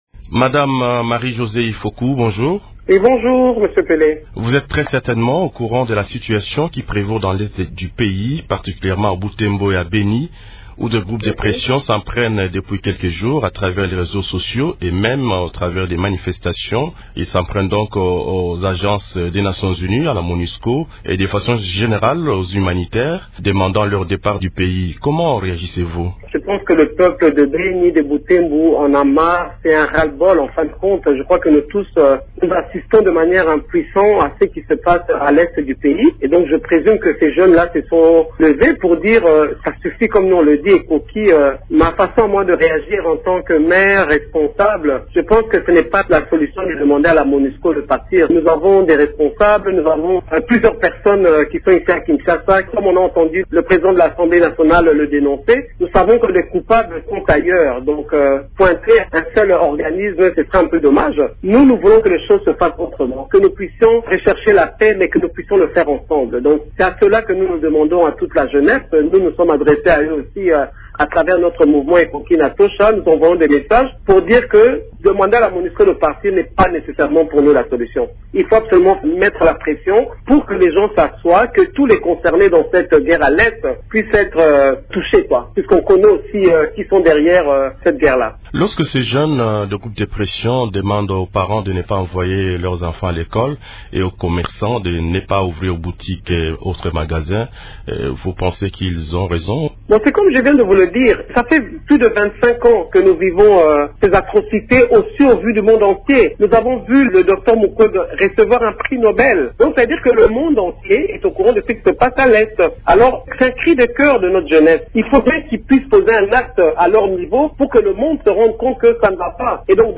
Invitée jeudi 8 avril de Radio Okapi, Marie-Josée Ifoku, demande aux organisateurs des manifestations à Beni et Butembo de ne pas recourir à la violence.
Mme Ifoku est aussi gouverneure honoraire de la Tshuapa et présidente de l’association Inatosha (Ekoki ou Ça suffit), qui lutte pour la paix et contre les violences sexuelles.